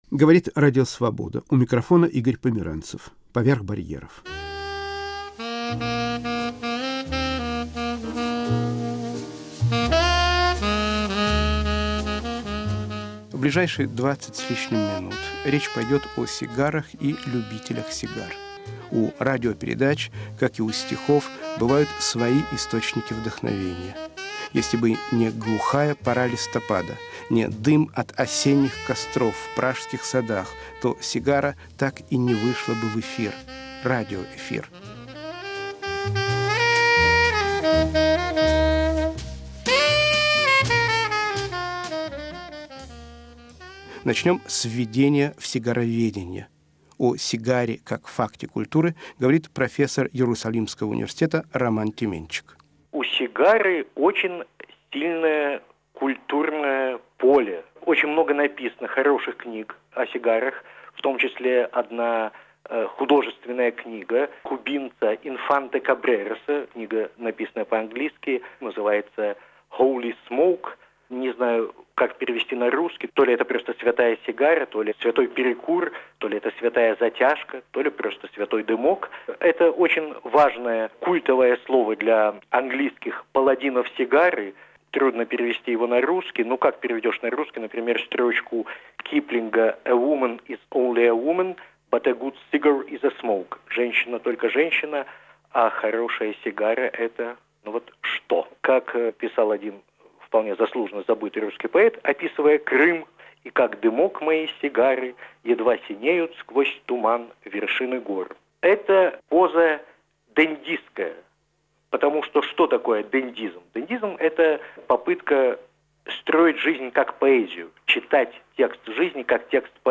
Передача с участием историка культуры, автора книги о сигарах, психолога и коллекционера.